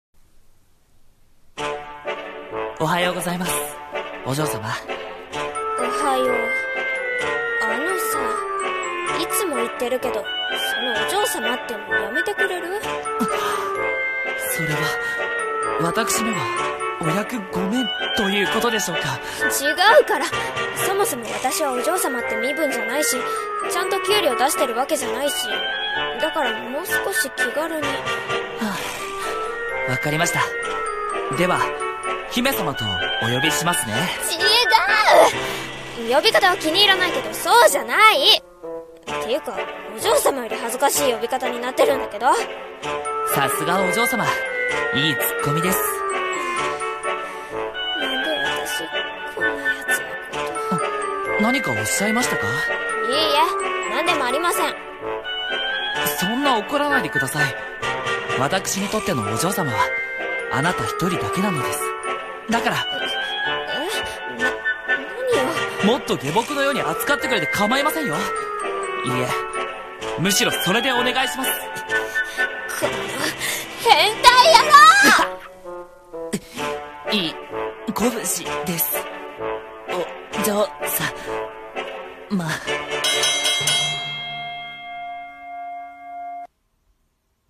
【声劇】お嬢様じゃなくて【掛け合い】